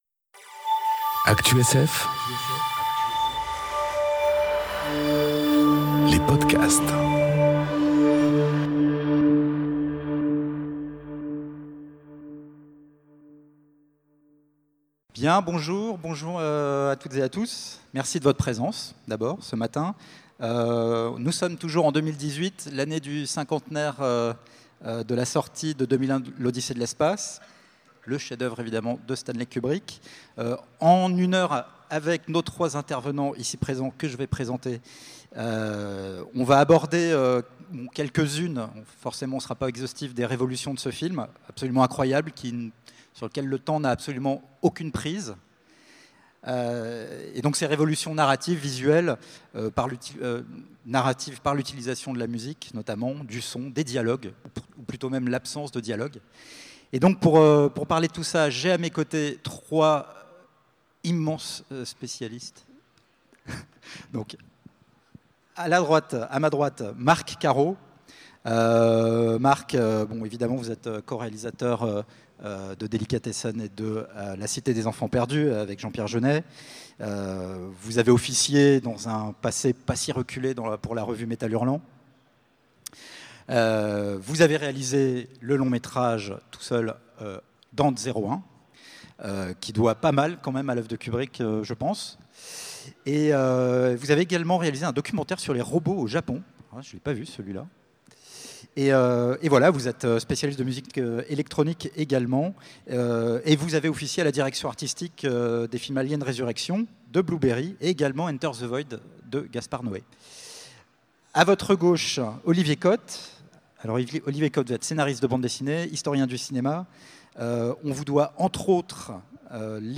Conférence Les 50 ans du film 2001, L’odyssée de l’espace enregistrée aux Utopiales 2018